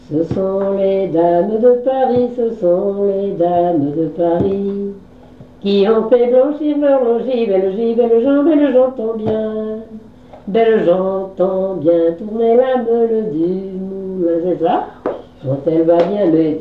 Chantée pour faire tourner les assiettes lors des noces
Pièce musicale inédite